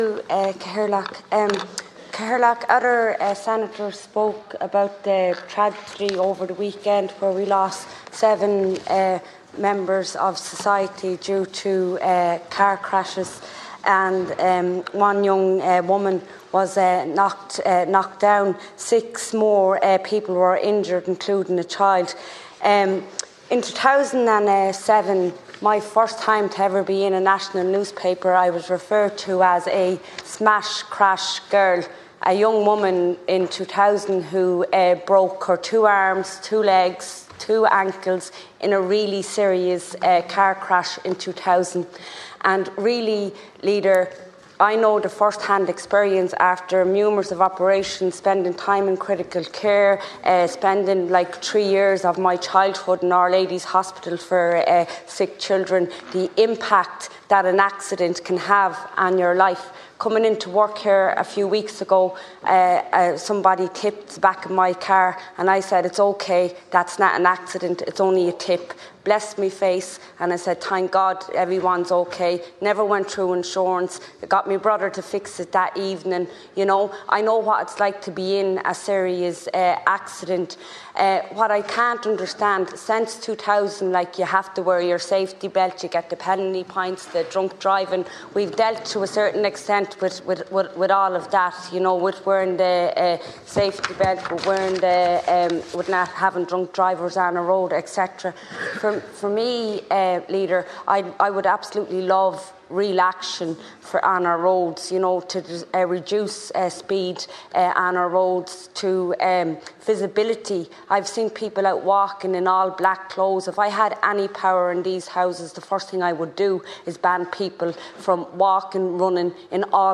Senator Eileen Flynn was speaking in the Seanad last week, following the deaths of seven people on Irish roads.